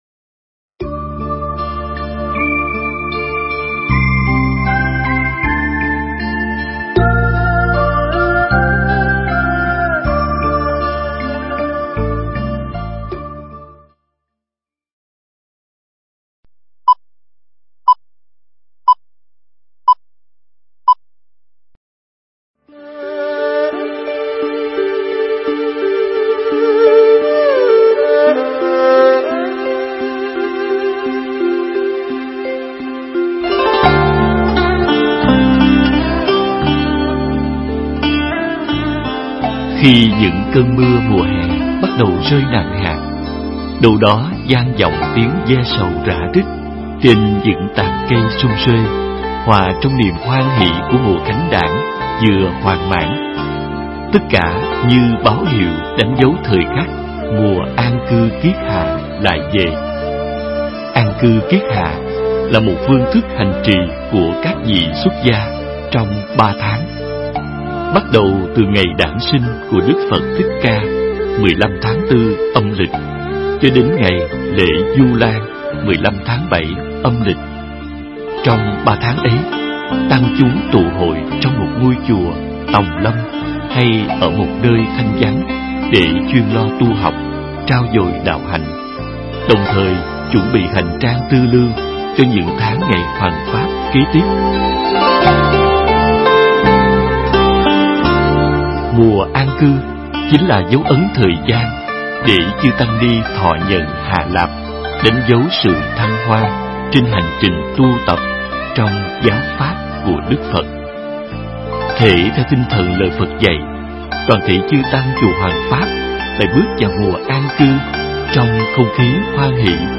Nghe Mp3 thuyết pháp An Cư Kiết Hạ